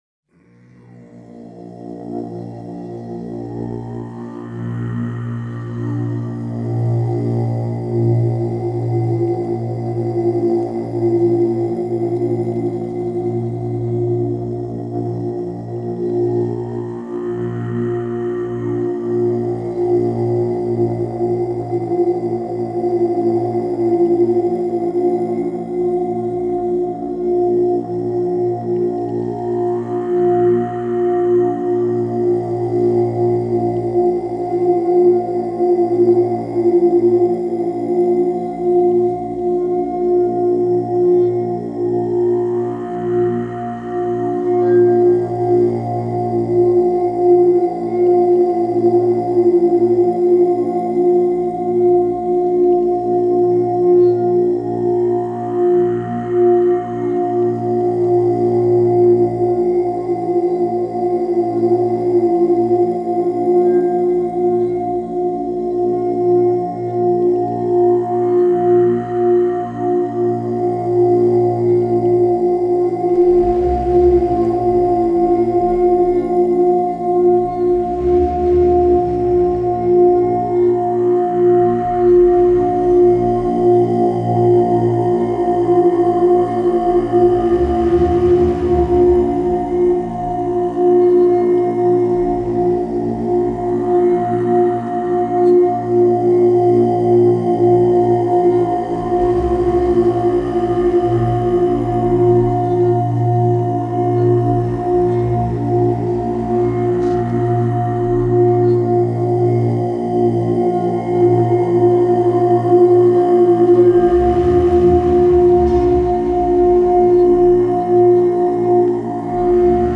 Музыка для медитации